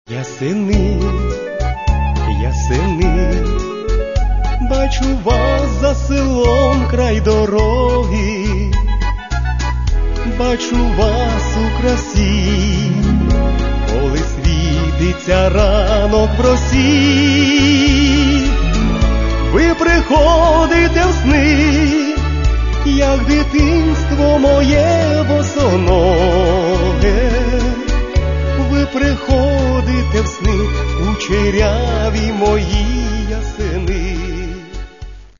Каталог -> MP3-CD -> Эстрада